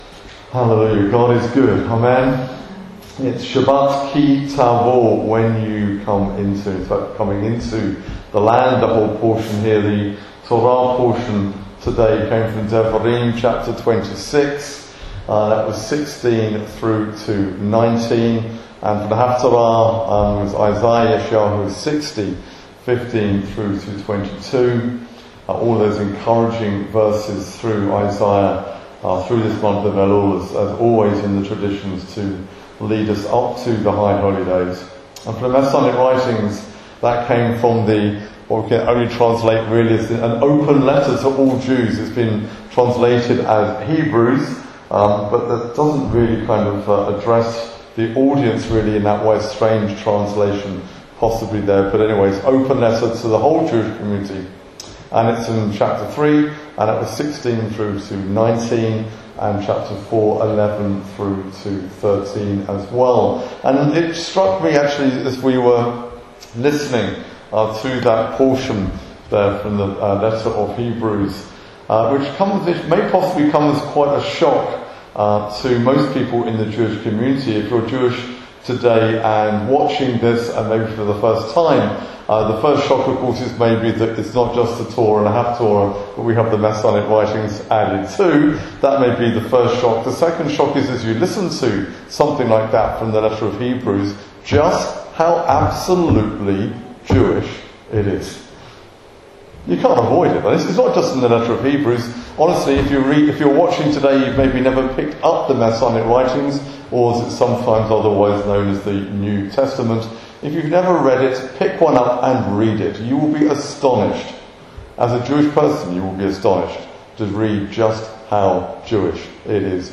July 2025 Sermon of the Month